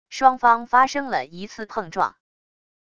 双方发生了一次碰撞wav音频生成系统WAV Audio Player